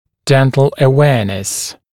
[‘dent(ə)l ə’weənəs][‘дэнт(э)л э’уэанэс]общая информированность о поддержании стоматологического здоровья